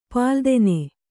♪ pāldene